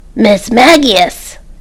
Cries
MISMAGIUS.mp3